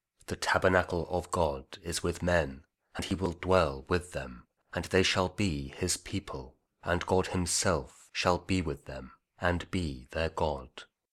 King James Audio Bible KJV | Revelation 21 | King James Version